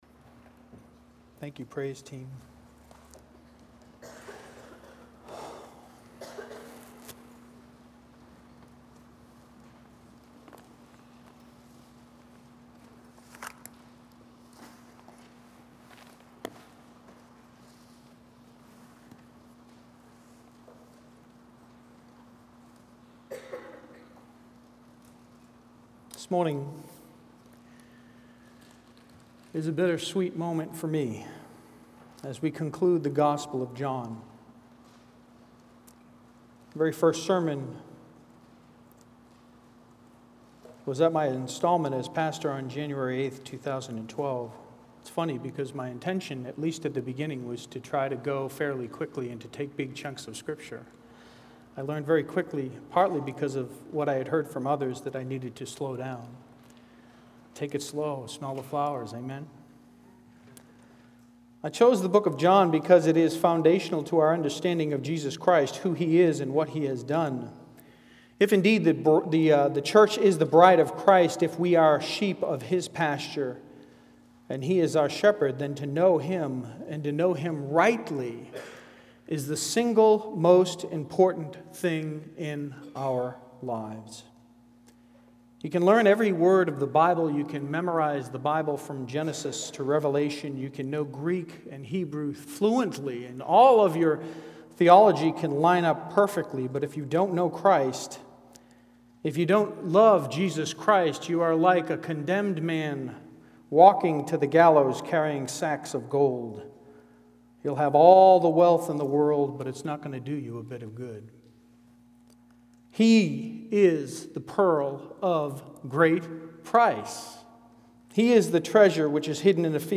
Trinity Bible Church of Fredericksburg Sermons, Expository Preaching, The Bo